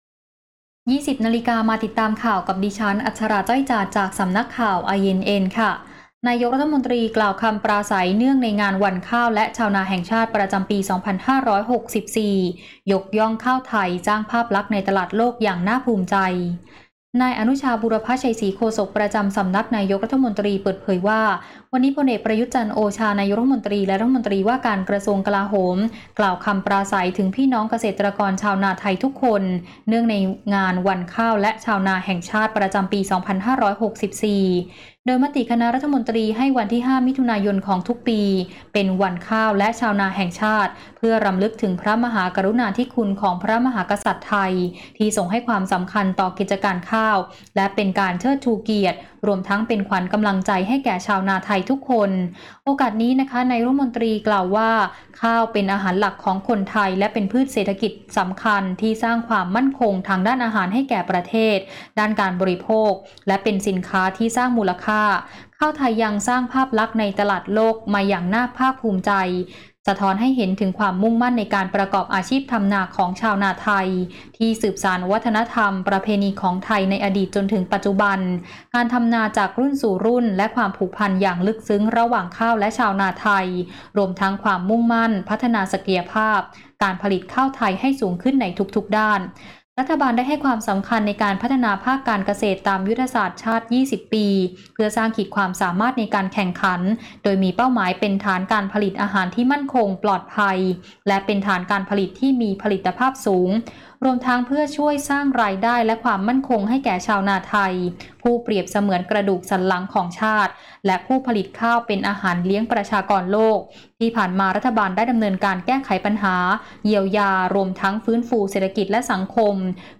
ข่าวต้นชั่วโมง 20.00 น.